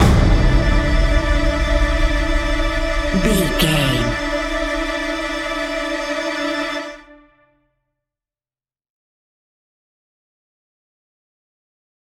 Hit With Trilling Cello.
Atonal
tension
ominous
eerie
drums
strings